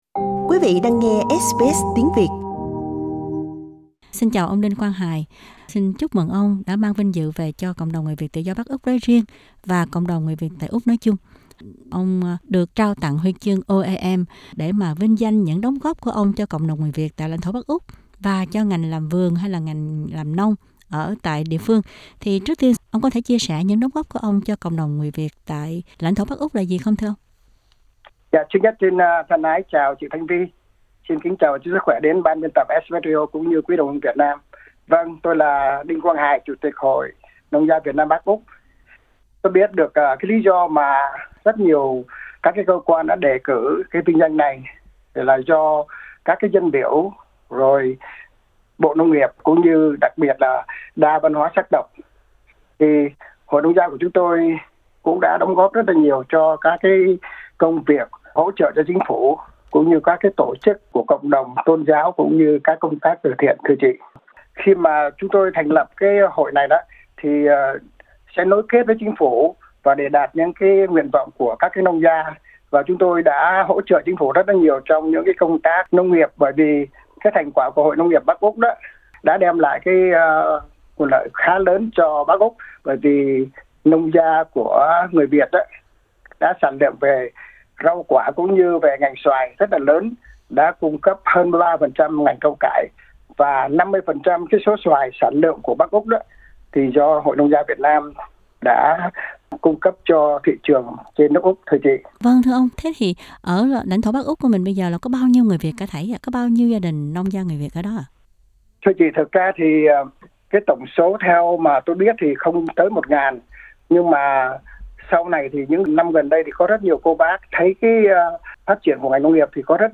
phần phỏng vấn